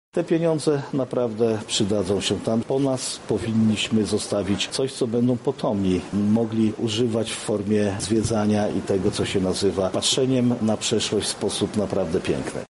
Dziedzictwo kulturowe i naturalne jest dla nas wielkim wyzwaniem – mówi marszałek województwa lubelskiego Jarosław Stawiarski